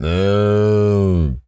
cow_moo_04.wav